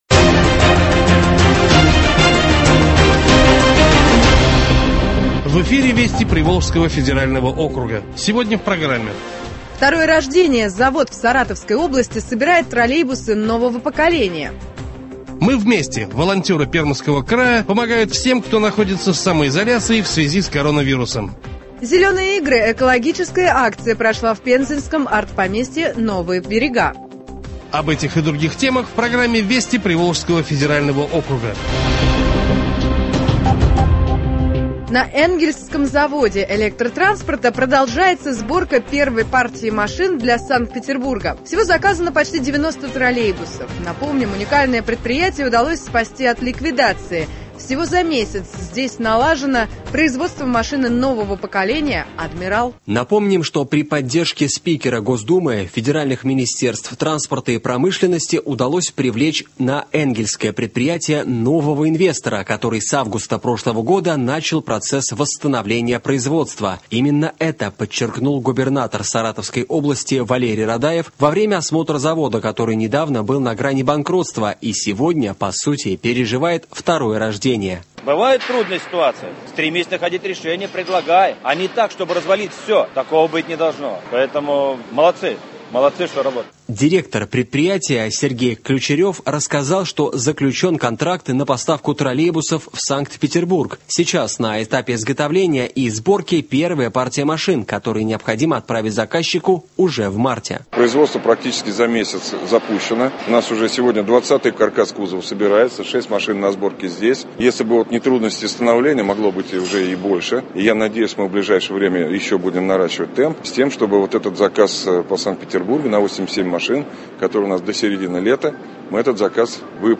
Радиоверсия телепрограммы, рассказывающей о событиях в Приволжском Федеральном округе.